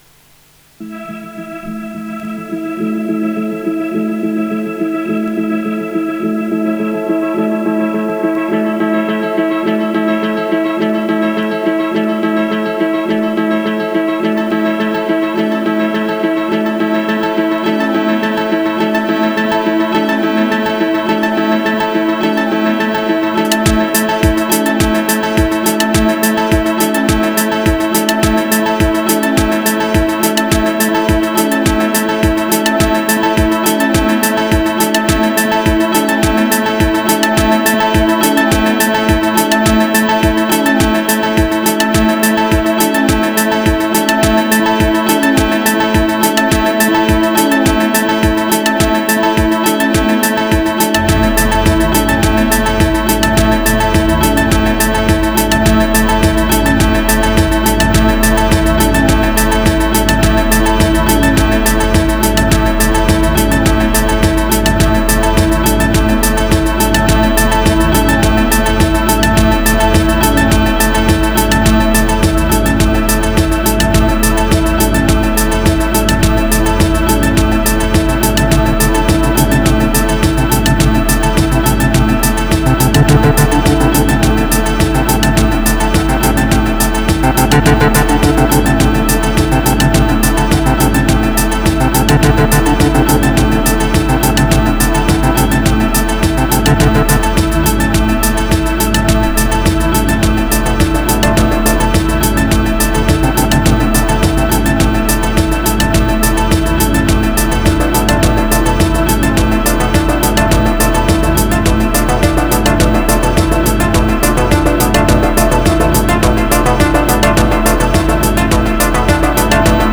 653📈 - 79%🤔 - 105BPM🔊 - 2025-08-17📅 - 568🌟
Leave the open samples, merges as track.